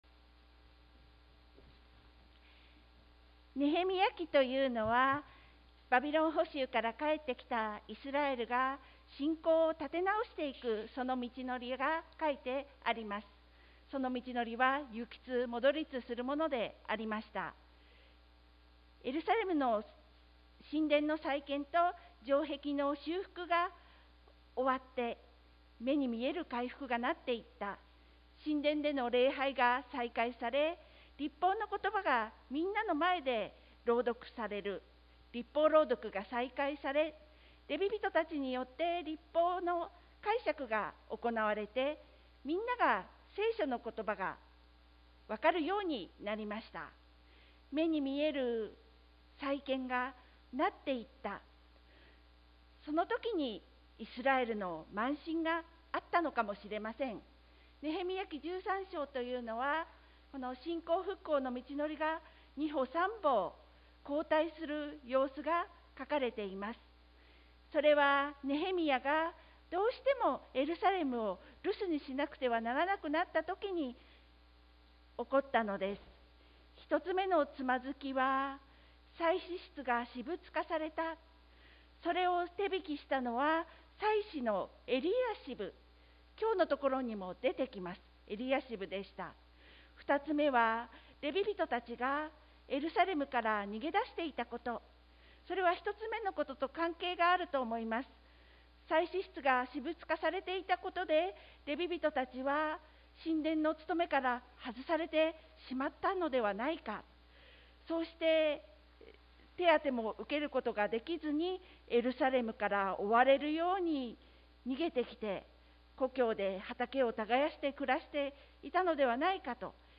sermon-2021-03-21